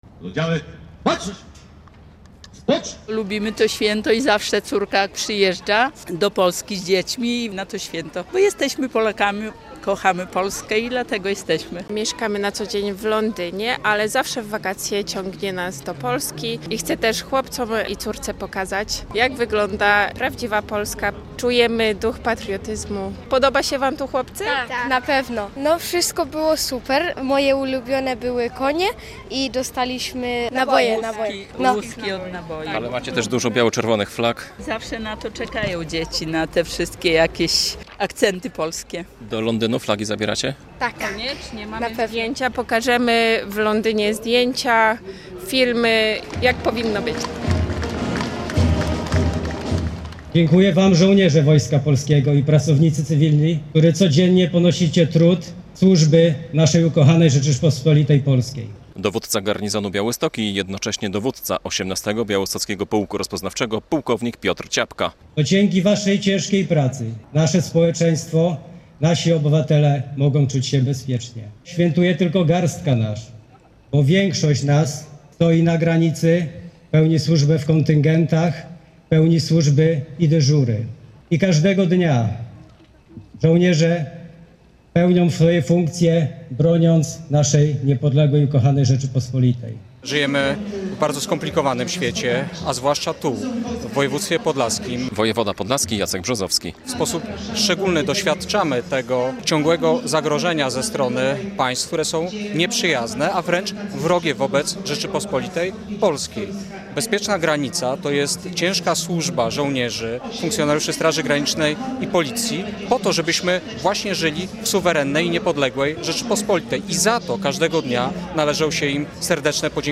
Obchody święta Wojska Polskiego w Białymstoku - relacja